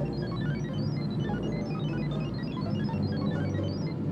BSG FX - Scanner
BSG_FX-Scanner.wav